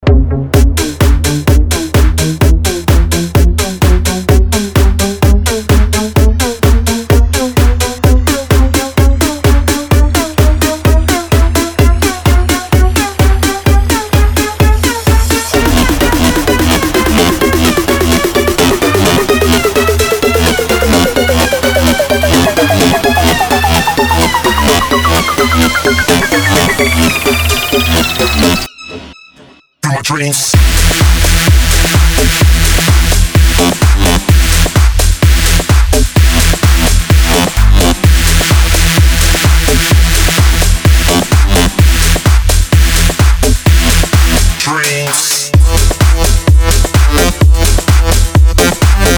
DJ and producer of tech house & house music
His style is unique and electrifying.
DJ